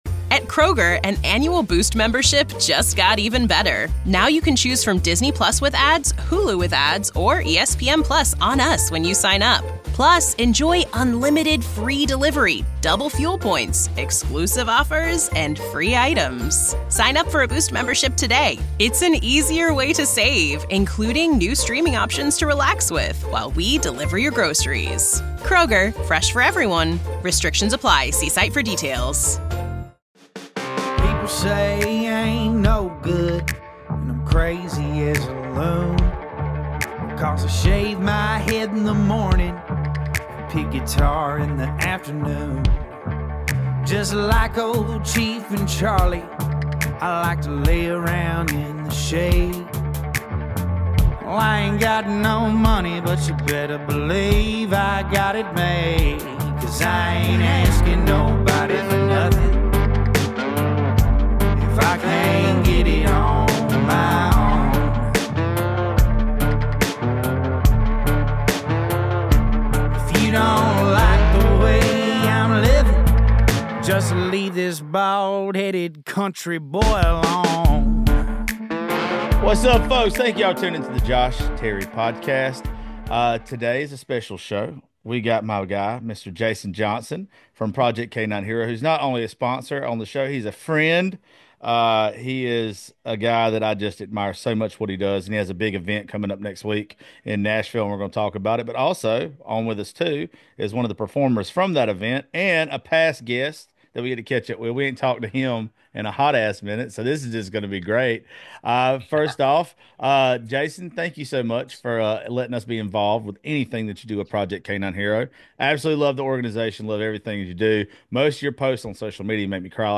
Imagine sitting at your favorite bar, meeting a complete stranger that slowly becomes your best friend as yall talk & debate about life, love, music & this crazy world we live in. The conversation might get a little crazy, a little loud, outta hand at times but somehow a solid point always seems to get made.